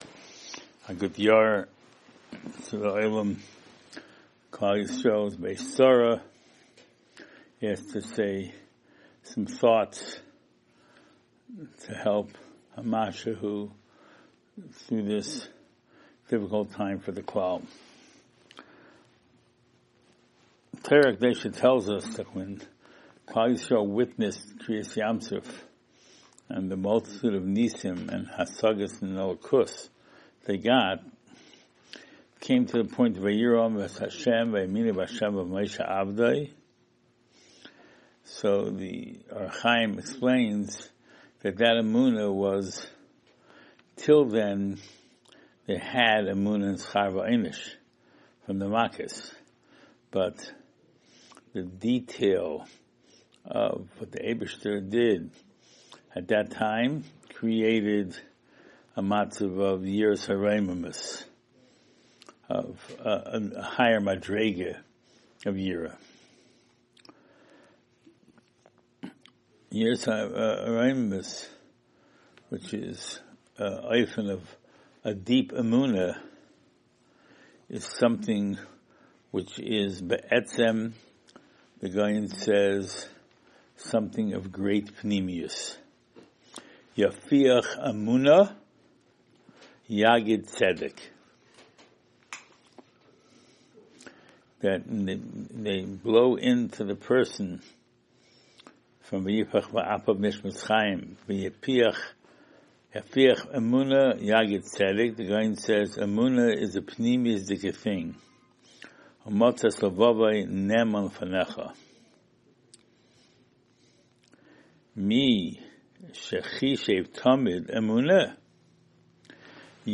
Special Lecture - Ner Israel Rabbinical College